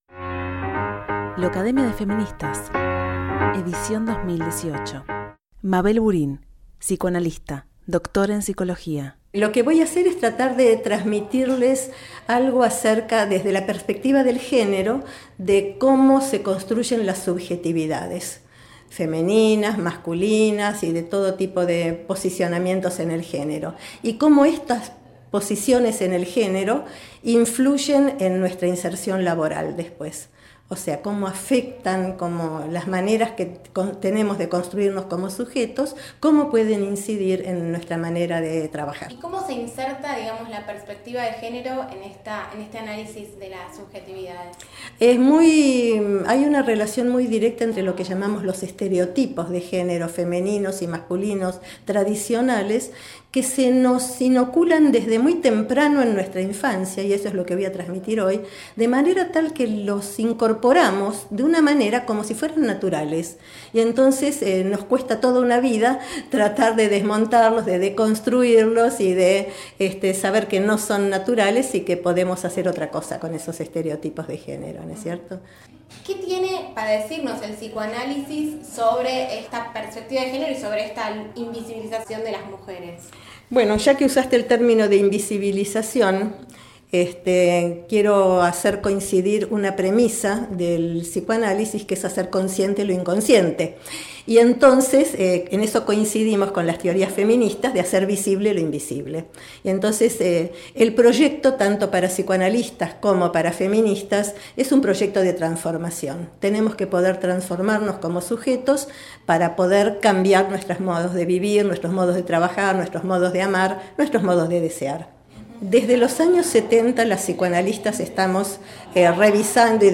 El primer encuentro de Locademia de feministas Edición 2018